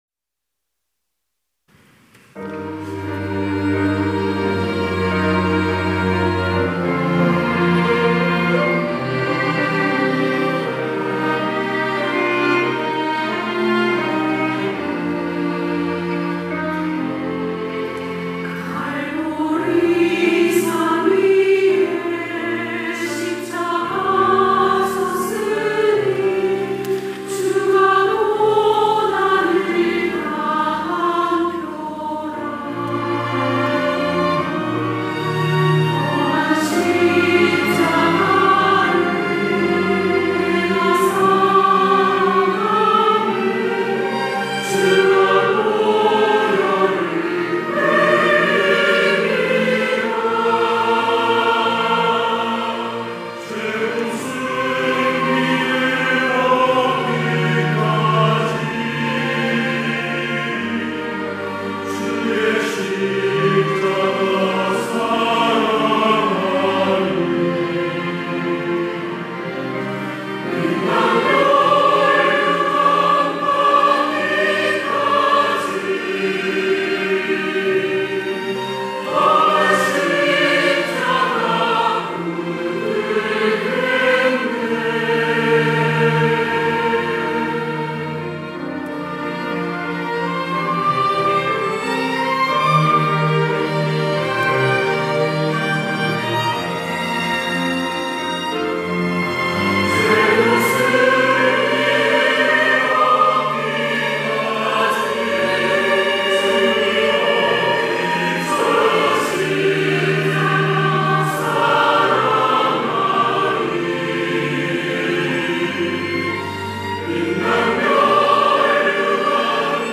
할렐루야(주일2부) - 갈보리 산 위에
찬양대